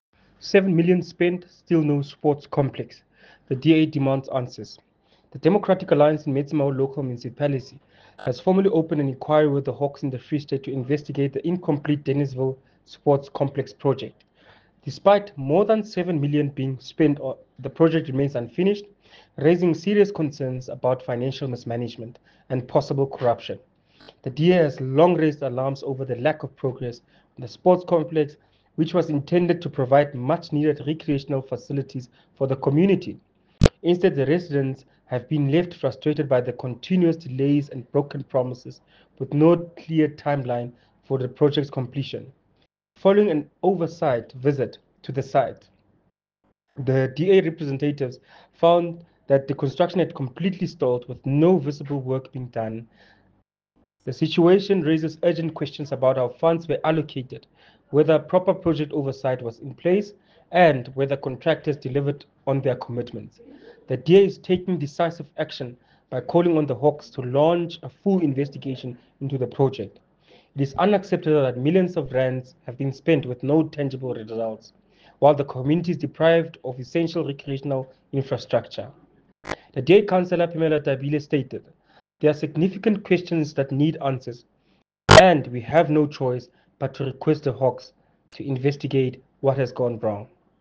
Afrikaans soundbites by Cllr Phemelo Tabile and